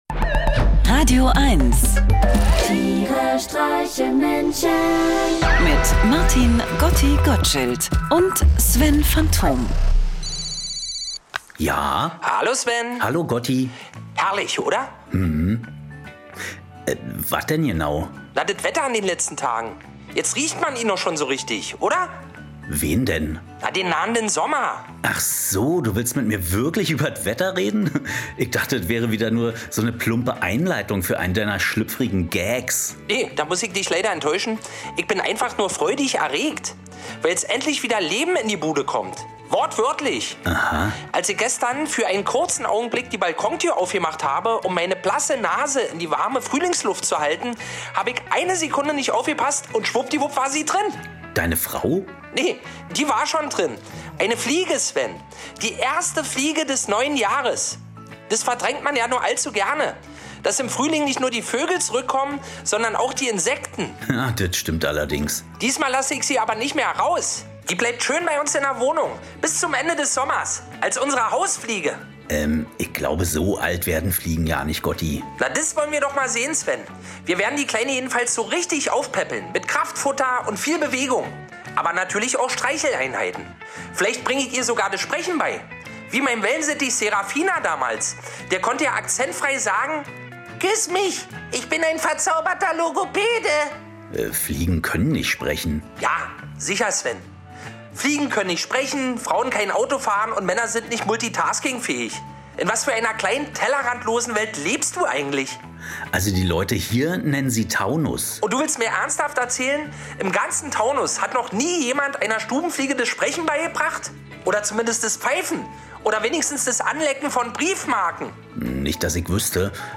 Einer liest, einer singt und dabei entstehen absurde, urkomische, aber auch melancholische Momente.
Comedy